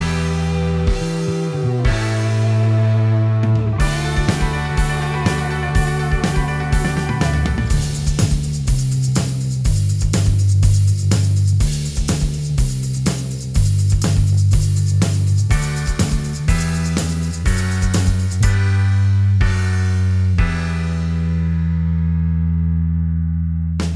Minus Lead Guitar Rock 2:40 Buy £1.50